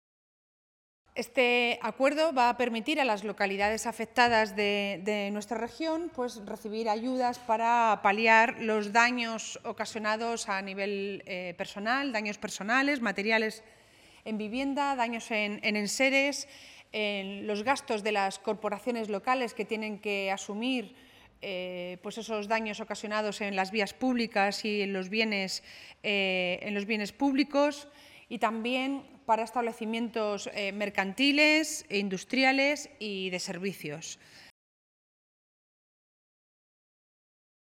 Consejería Portavoz Miércoles, 2 Abril 2025 - 1:45pm La consejera Portavoz ha dicho que el acuerdo adoptado por el Consejo de Gobierno va a permitir a las localidades afectadas de nuestra región recibir ayudas para paliar los daños ocasionados a nivel personal, daños materiales en vivienda, daños en enseres, en los gastos de las corporaciones locales como consecuencia de los daños ocasionados en las vías y bienes públicos, así como también para establecimientos mercantiles, industriales y de servicios. esther_padilla_ayudas.inundaciones.mp3 Descargar: Descargar